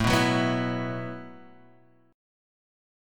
A 6th Suspended 2nd